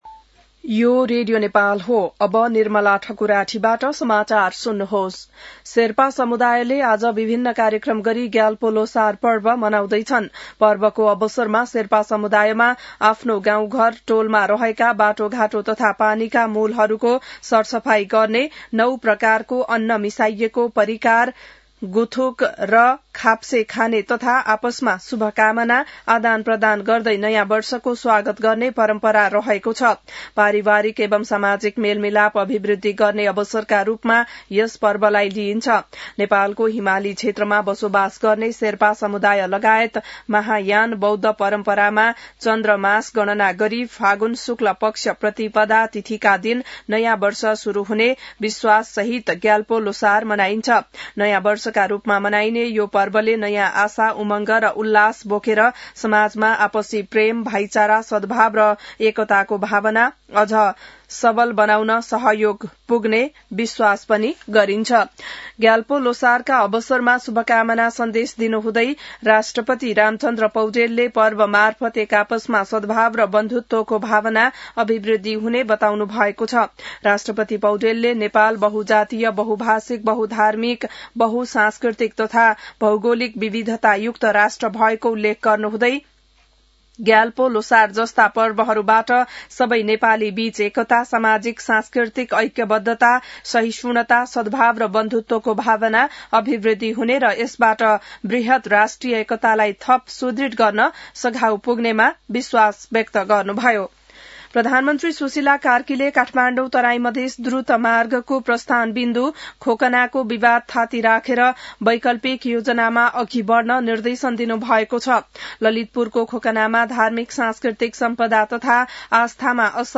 बिहान १० बजेको नेपाली समाचार : ६ फागुन , २०८२